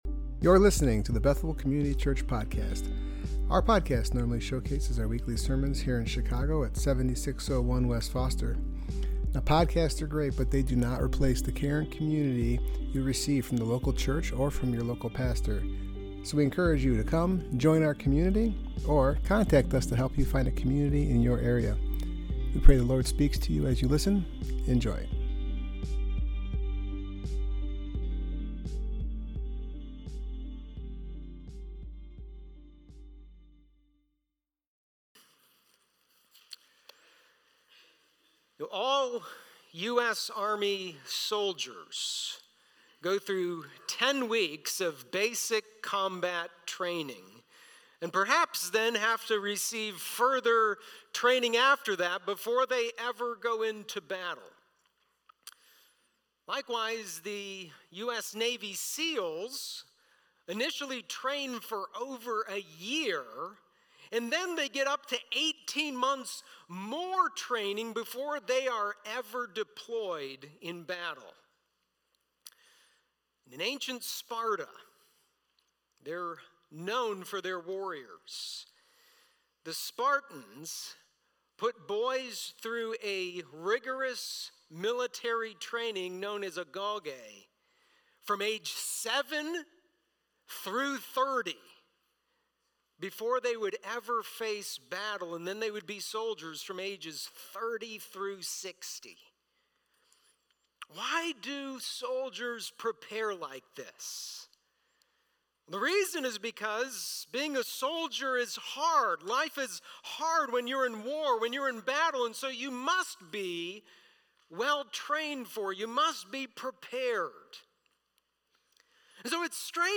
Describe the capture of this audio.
Service Type: Worship Gathering Topics: armor of God , Satan the enemy , strength in the Lord